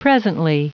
Prononciation du mot presently en anglais (fichier audio)
Prononciation du mot : presently